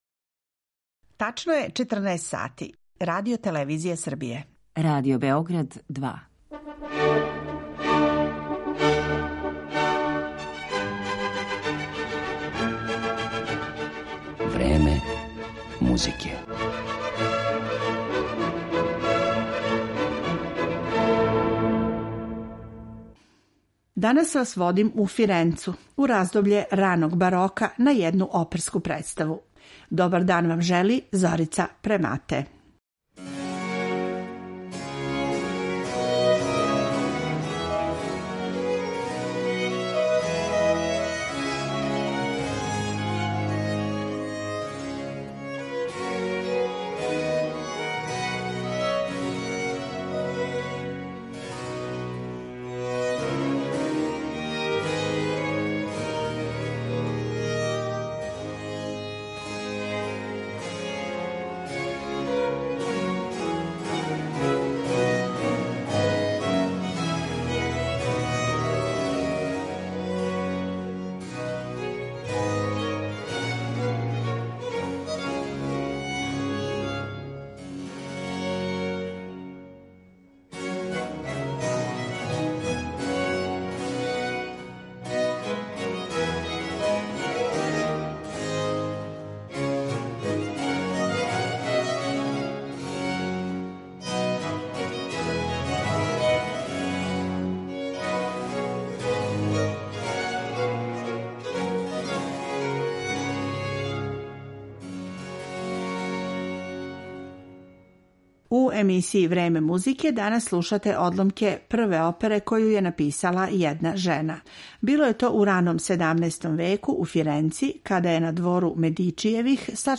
У емисији Време музике слушаћете одломке из прве сачуване опере коју је написала жена.
Слушаћете снимак који су остварили солисти Варшавске камерне опере и ансамбла за стару музику „ Musicae antique Collegium Varsoviense ", који свира на копијама оригиналних инструмената тог доба.